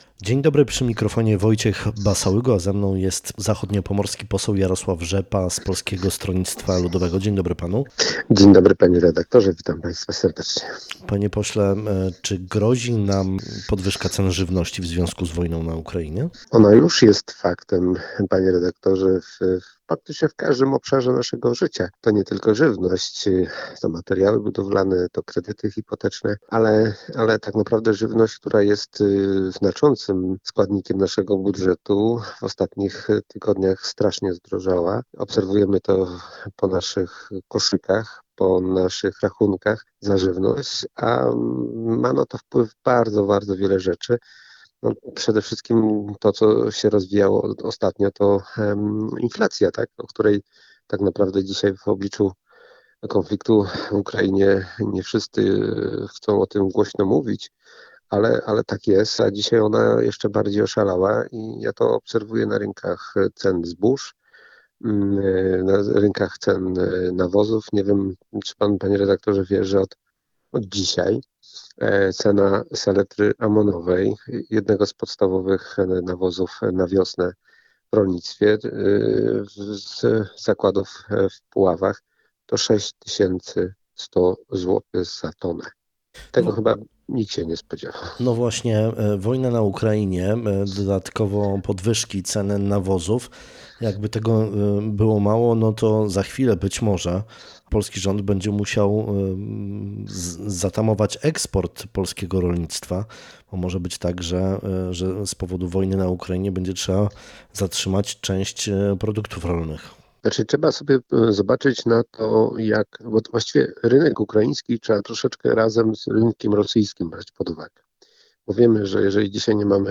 Zachodniopomorski poseł z Polskiego Stronnictwa Ludowego, Jarosław Rzepa był naszym gościem w dzisiejszej Rozmowie Dnia. Czy grozi nam podwyżka cen żywności w związku z wojną na Ukrainie?